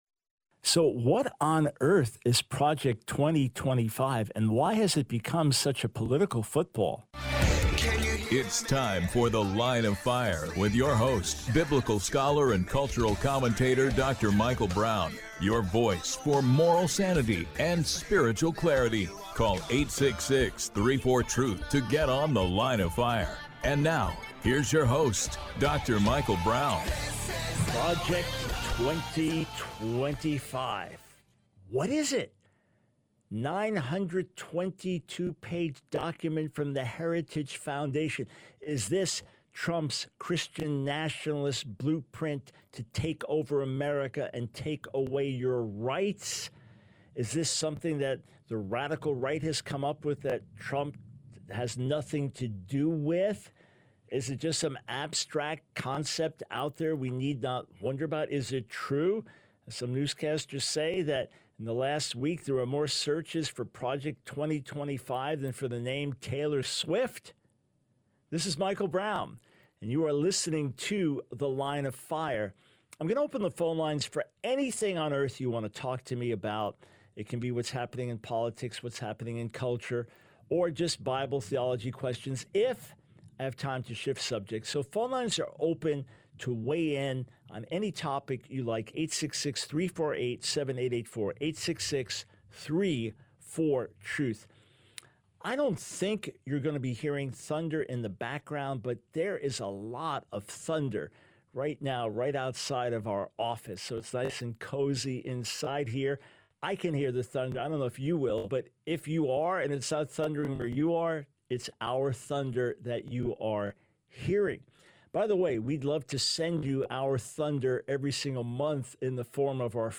The Line of Fire Radio Broadcast for 07/23/24.